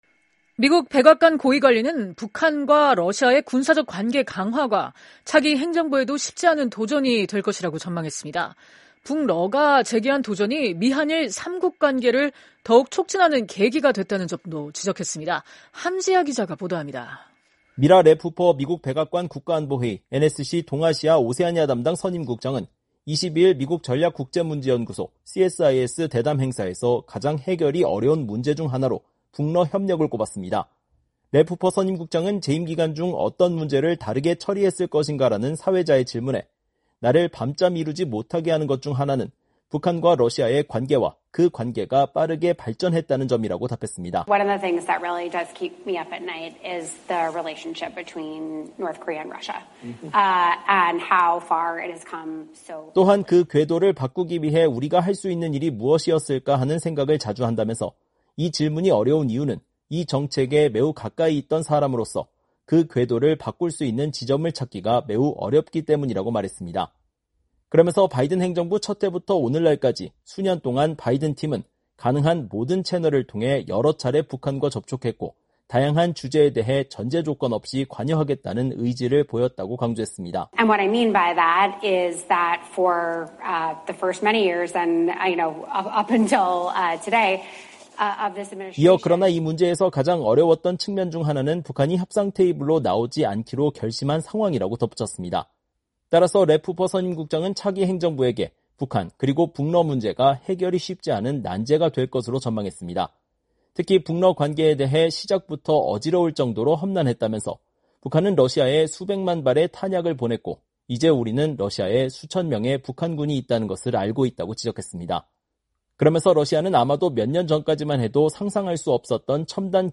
미라 랩-후퍼 미국 백악관 국가안보회의(NSC) 동아시아∙오세아니아 담당 선임국장이 22일 미국 전략국제문제연구소(CSIS) 대담 행사에서 발언하고 있다.